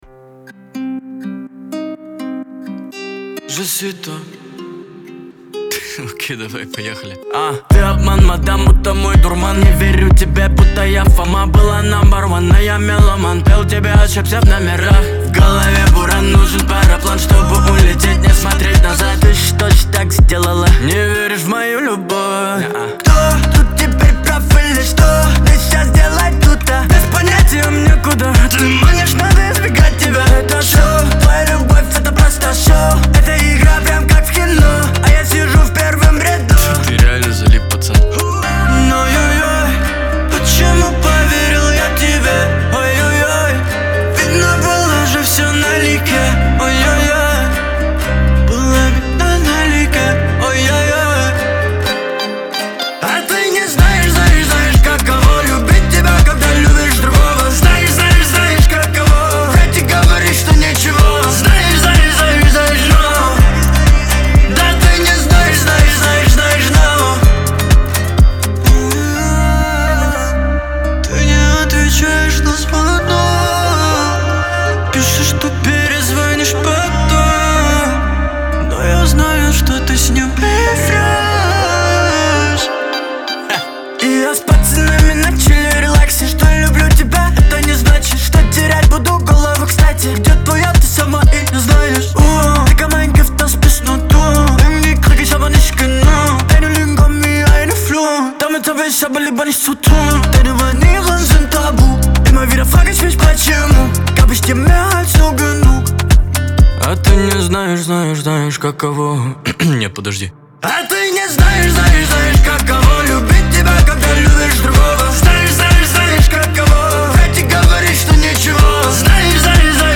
Hiphop Rap